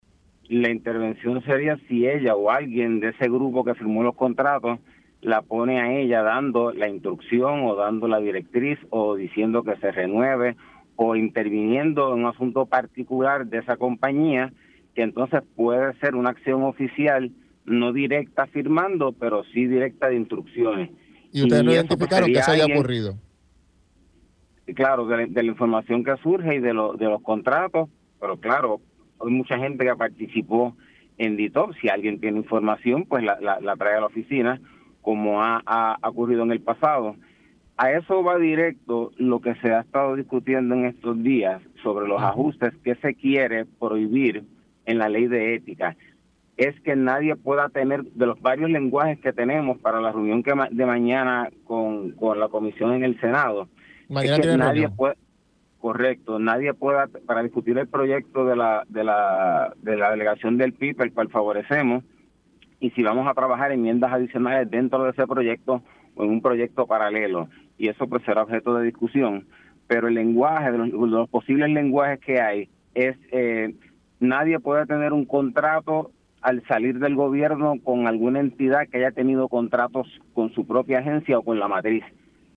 412-LUIS-PEREZ-DIR-DE-ETICA-PROPONDRAN-ENMIENDAS-EN-LENGUAJE-A-LEY-DE-ETICA.mp3